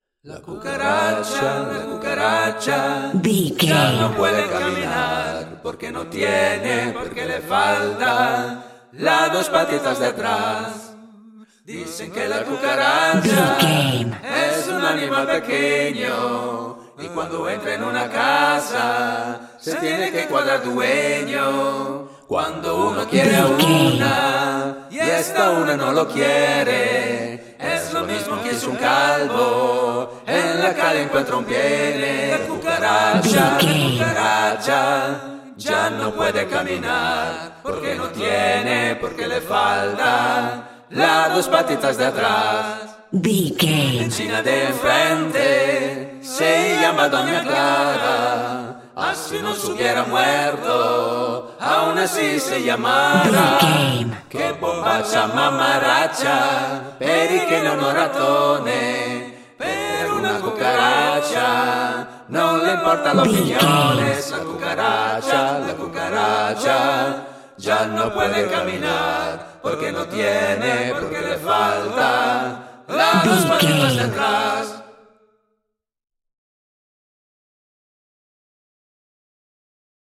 Ionian/Major
fun
groovy